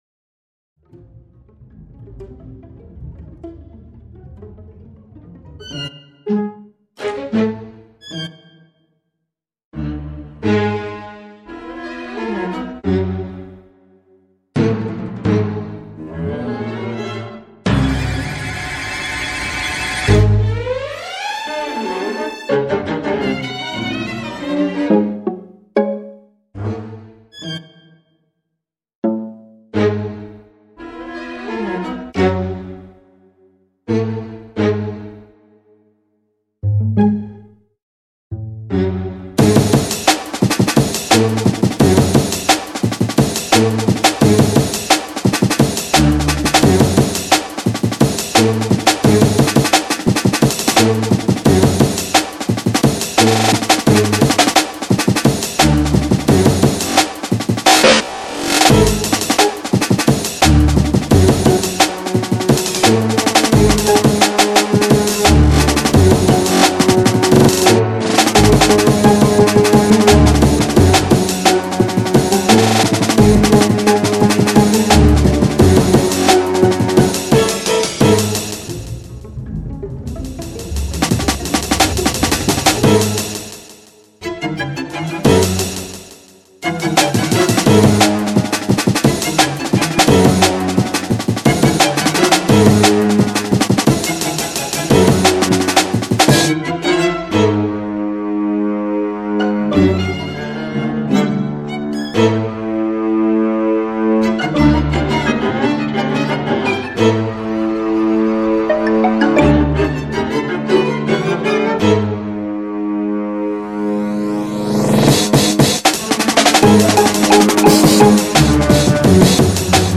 drum and bass and breakbeat